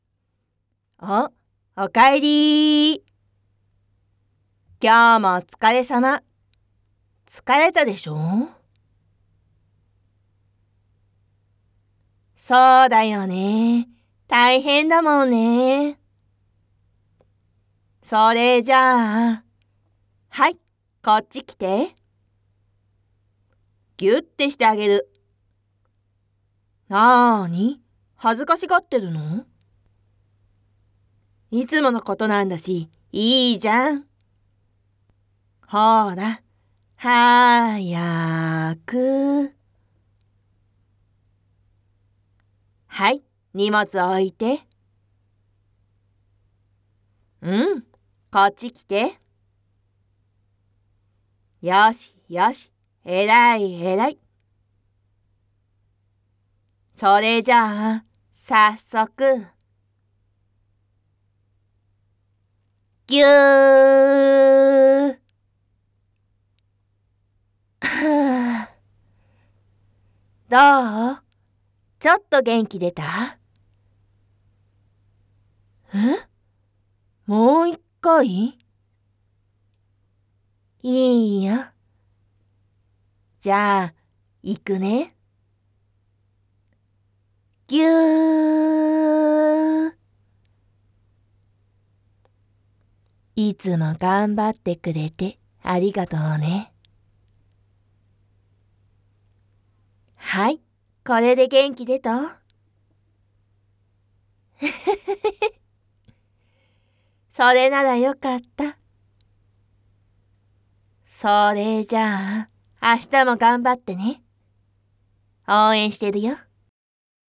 纯爱/甜蜜 日常/生活 温馨 萌 健全 治愈 皆大欢喜 催眠音声